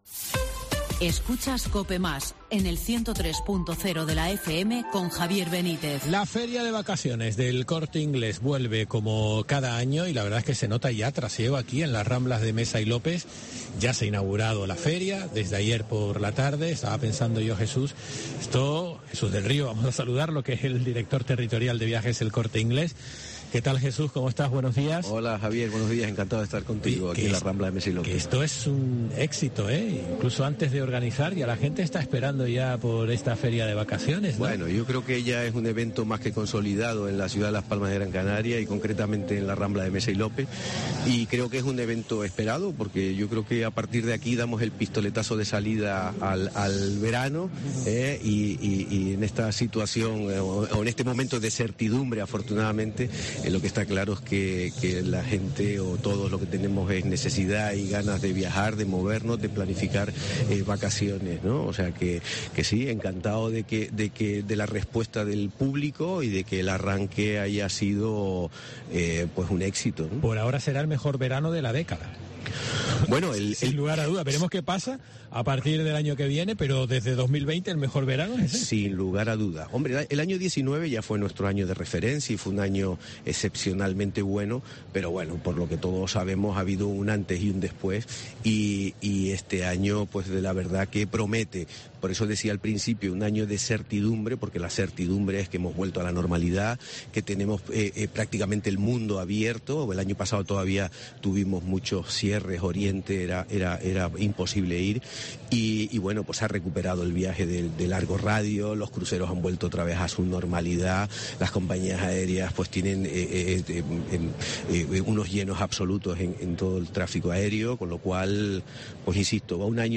La Mañana de COPE Gran Canaria se ha desplazado a las ramblas donde más de 40 operadores turísticos, entre navieras, compañías aéreas, hoteles, apartamentos e incluso parques temáticos y de ocio, presentarán ofertas exclusivas para la feria, la cita comenzó el jueves 19 y se prolongará hasta el próximo sábado entre las 16 horas y hasta las 9 de la noche.